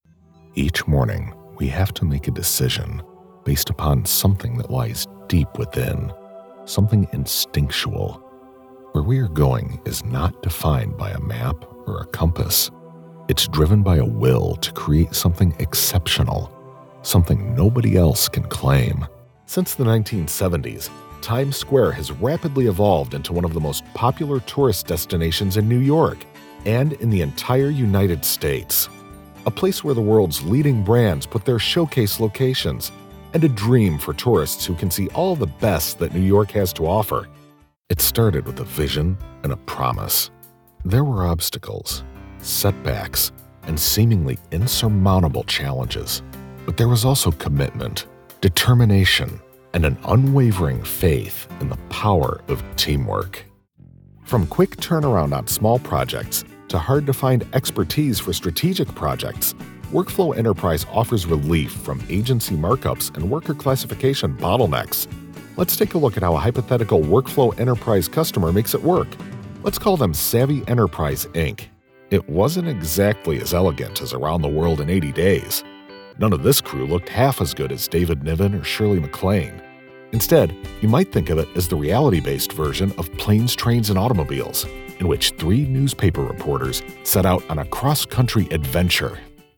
Adult
Has Own Studio
standard us
documentary
e-learning
authoritative
dramatic
gravitas